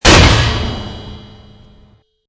CHQ_FACT_stomper_large.mp3